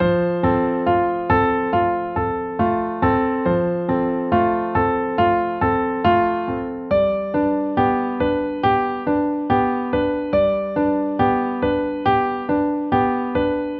向上向下钢琴
Tag: 139 bpm Cinematic Loops Piano Loops 2.32 MB wav Key : Unknown